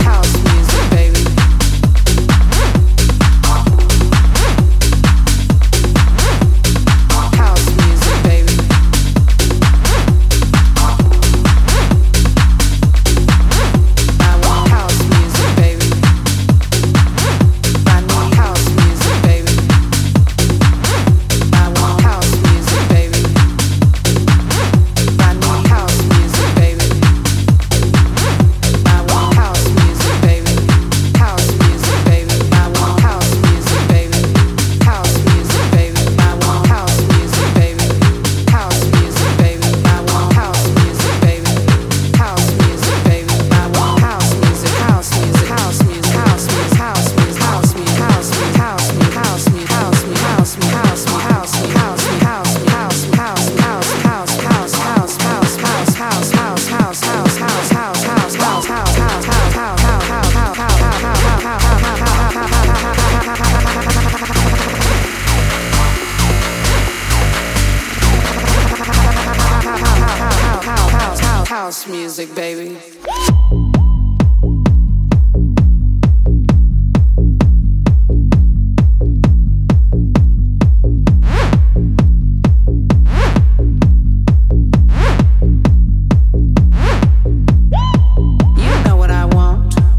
ブーストしたベースラインの疾走感でフロアの熱量を持続させる